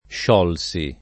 +š0lSi]; part. pass. sciolto [